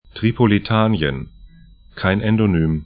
Tripolitanien tripoli'ta:nĭən Ţarābulus ar Gebiet / region 30°13'N, 15°13'E